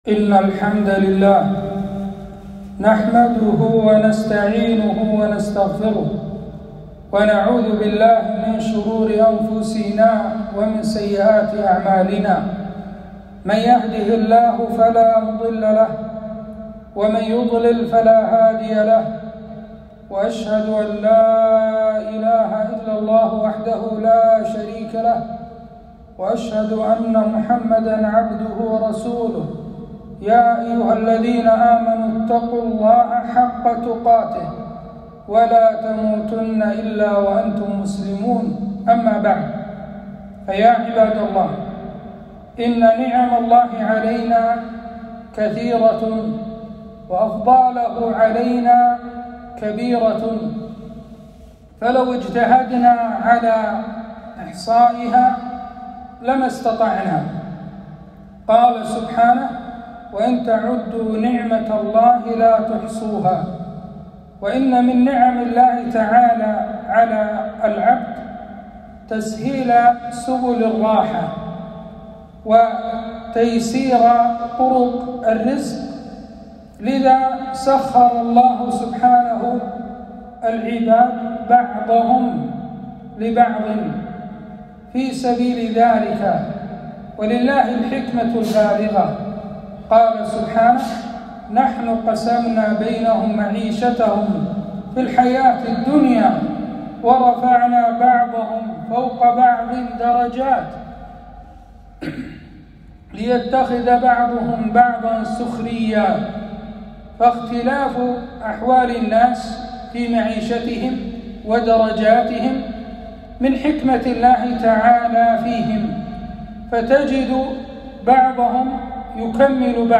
خطبة - حقوق العمال في الإسلام